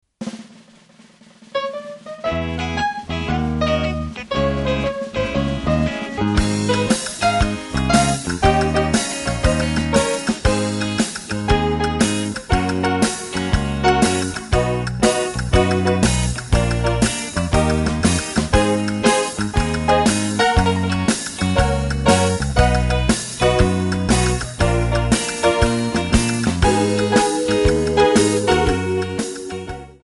Backing track files: 1960s (842)